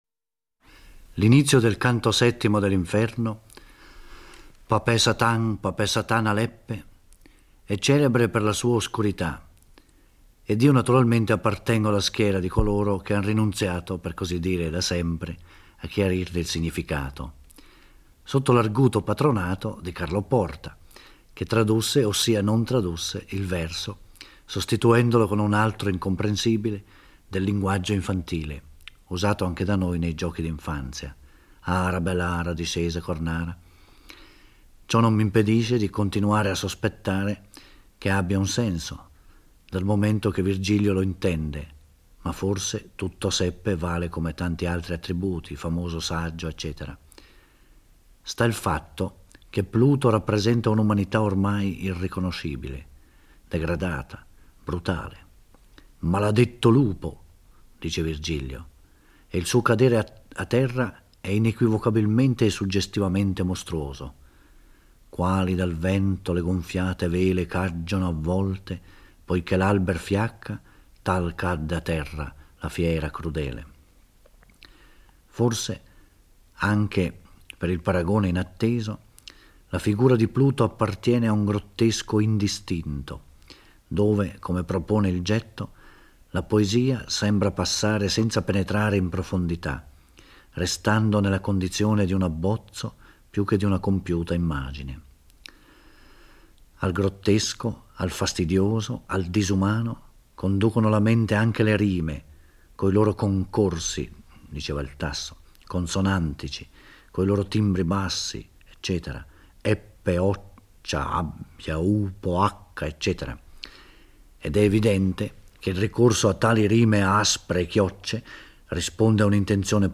Giorgio Orelli legge e commenta il VII canto dell'Inferno. Siamo nel quarto cerchio, custodito dal demone Pluto, il dio greco della ricchezza, dove ci sono gli avari e i prodighi, condannati a spingere col petto pesanti macigni e ad azzuffarsi e insultarsi l'uno con l'altro.